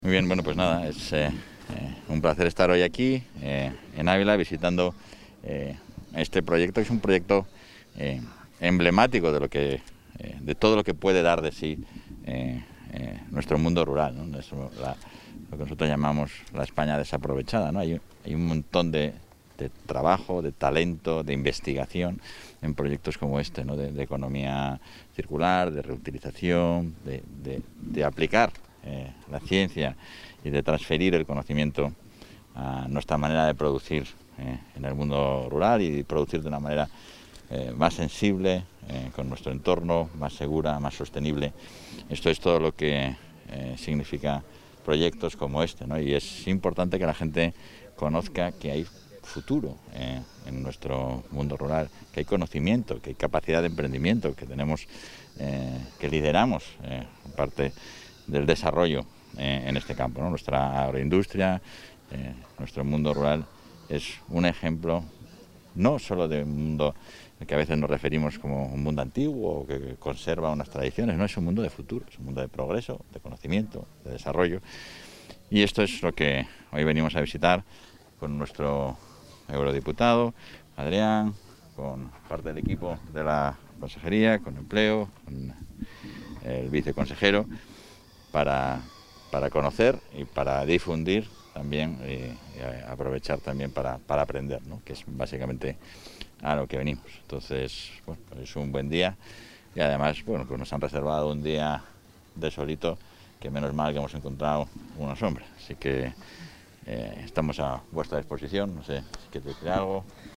Declaraciones del vicepresidente de la Junta.
Declaraciones del vicepresidente de la Junta El vicepresidente atiende a los medios de comunicación En Avicogan - Grupo Kerbest, en Villanueva de Gómez Visita a las instalaciones de Digitanimal Visita a las instalaciones de Digitanimal